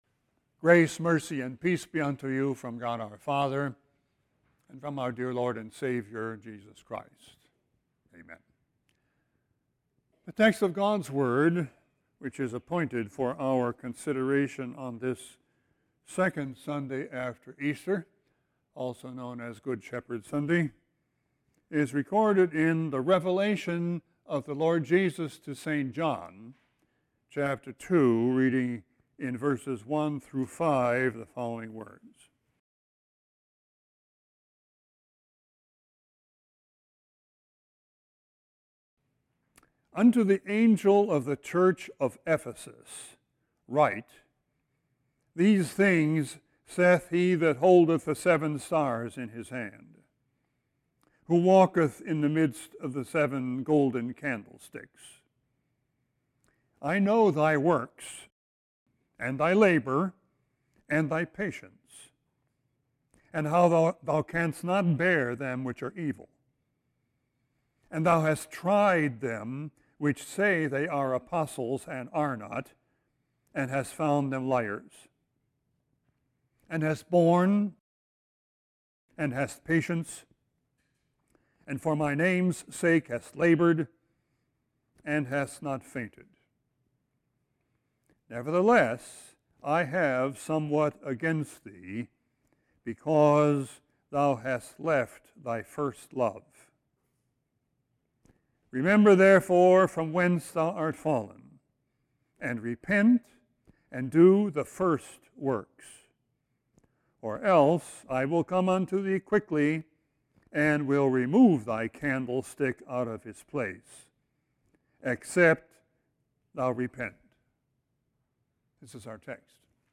Sermon 4-15-18.mp3